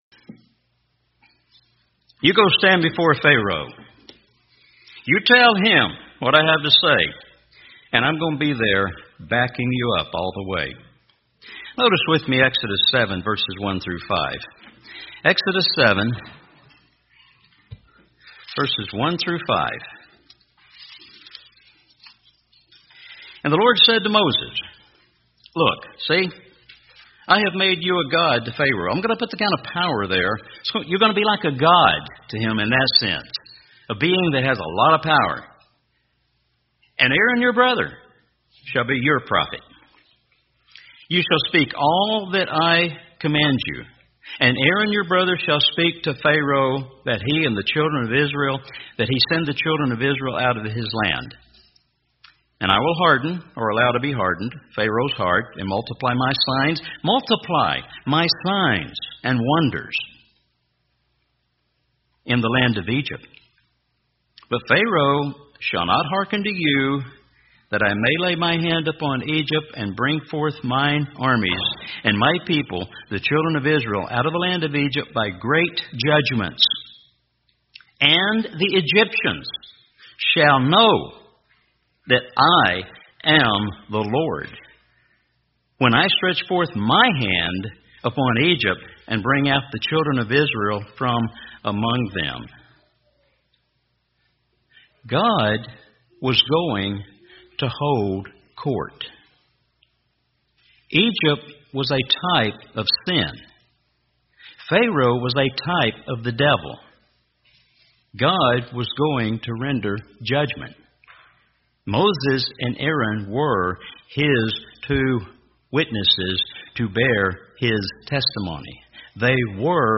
Such a great sermon on the Feast of Trumpets. Using the analogy of holding court puts many things about this important day in a new light. Consider Egypt as a type of sin and Pharoah as a type of Satan.